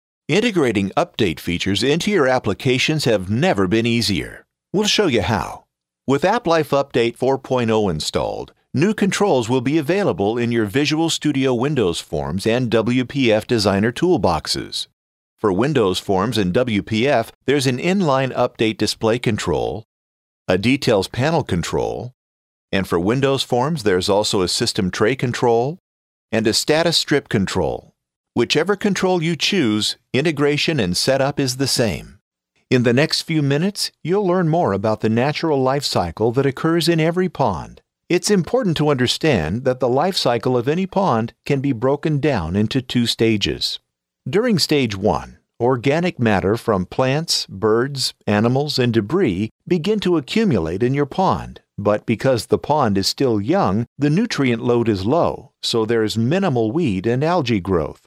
Male, corporate, commercial, friendly, announcer, guy next door, warm
Sprechprobe: Industrie (Muttersprache):